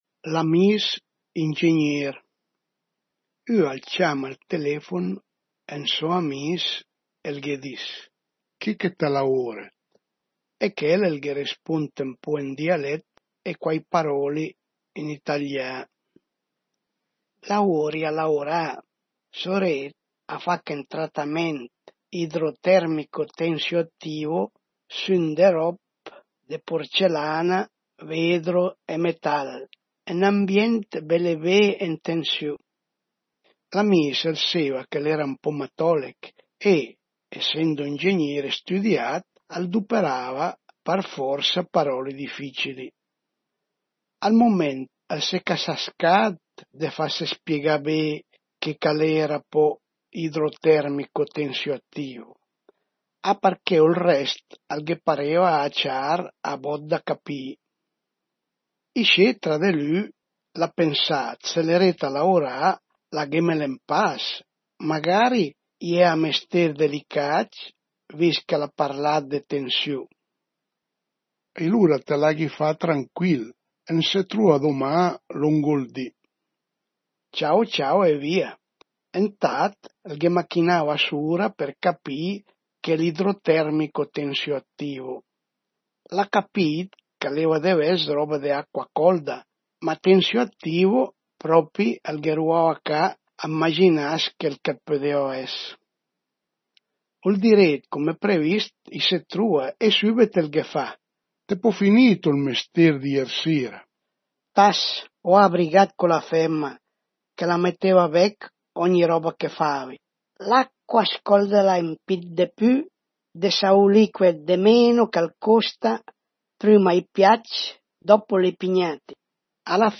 L'amìis ingignéer | Dialetto di Albosaggia